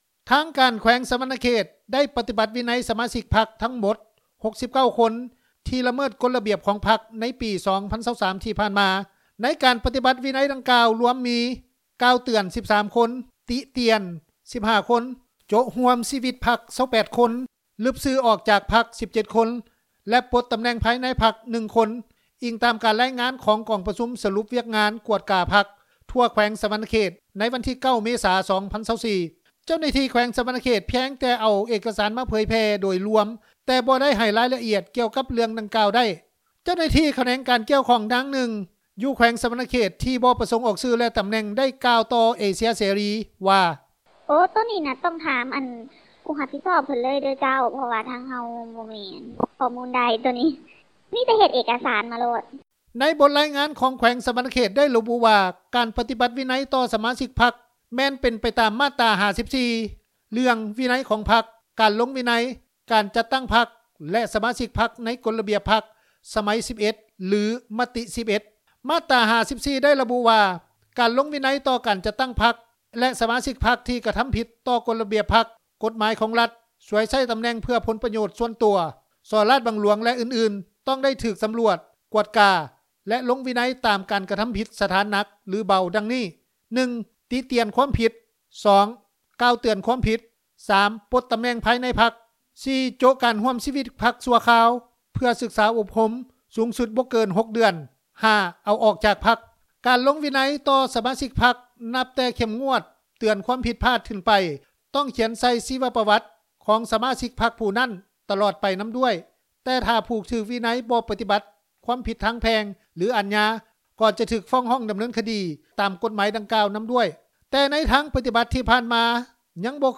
ຊາວລາວ ທີ່ເຂົ້າມາເຮັດວຽກຢູ່ປະເທດໄທ ທ່ານ ໜຶ່ງ ໄດ້ກ່າວວ່າ:
ຊາວລາວ ອີກນາງນຶ່ງ ກ່າວວ່າ: